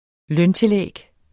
Udtale [ ˈlœn- ]